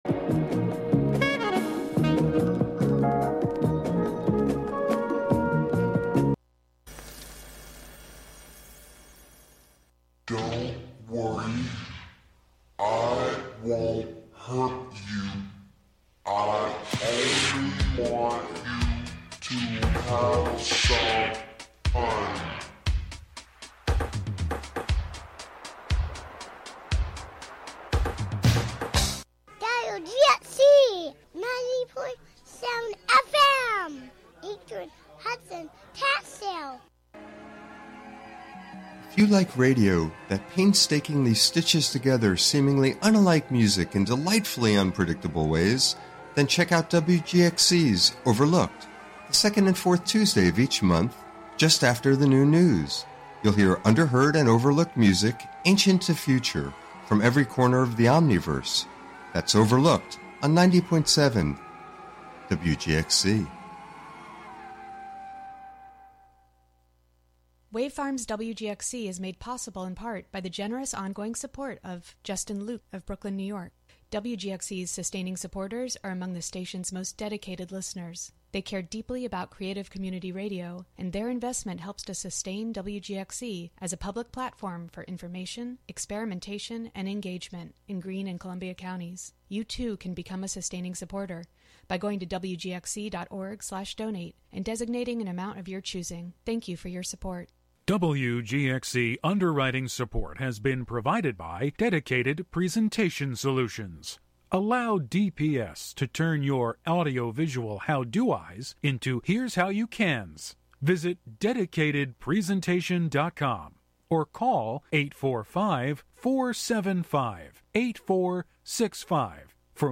In these hour-long broadcasts ordinarily quiet devices, spaces, and objects within the house are amplified and harmonized into song using sensors and mics then streamed live.
"Home Song #1" adapts these myths to present an alternative to the hierarchies implicit in the contemporary flows of capital, objects, and labor as they are played out in the home. Instead of being silent, a fridge sings of ice and freon, a router sings of packets of data coming and going, a washing machine sings about the love embedded in making mucky things fresh and clean for a new day, and these songs combine to produce a chorus that meets the world through the throat of a live stream over the internet.